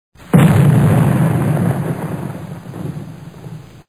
SFX战争中炮声响起音效下载
SFX音效